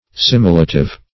Search Result for " similative" : The Collaborative International Dictionary of English v.0.48: Similative \Sim"i*la*tive\, a. Implying or indicating likeness or resemblance.